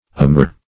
Amour \A*mour"\, n. [F., fr. L. amor love.]